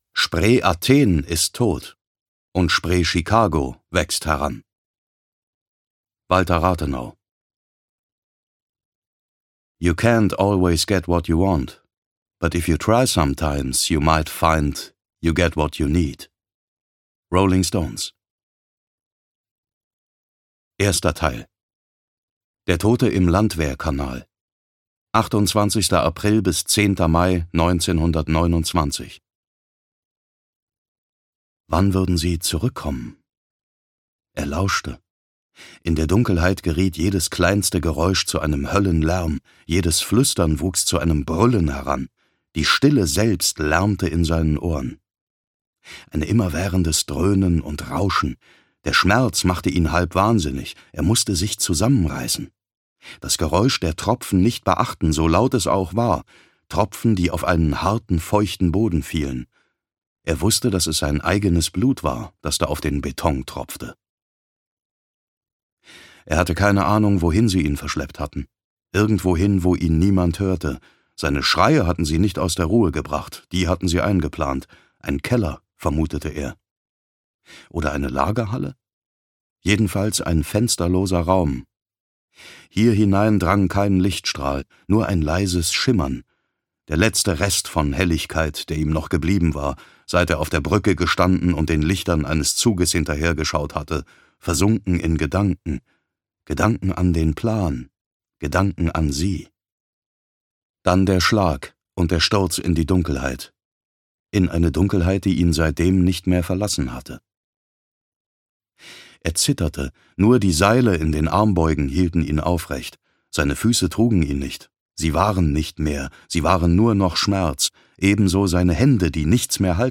Der nasse Fisch (DE) audiokniha
Ukázka z knihy
• InterpretDavid Nathan